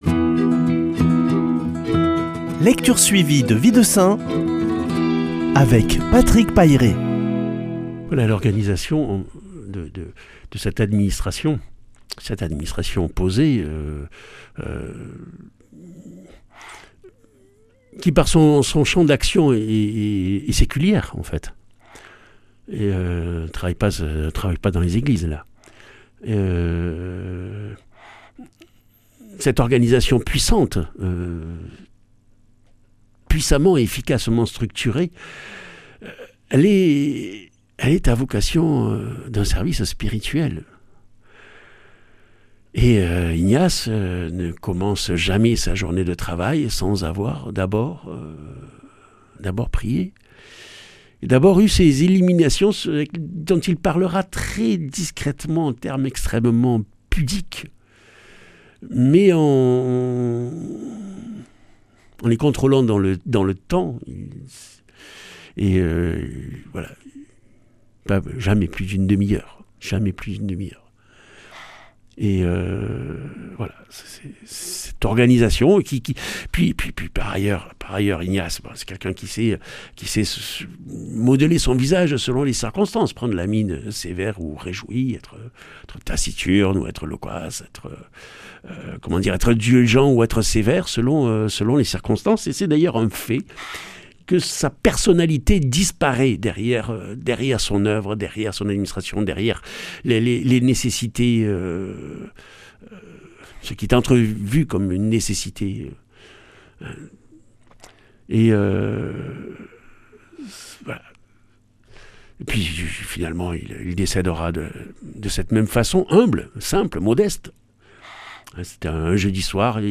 Lecture suivie de la vie des saints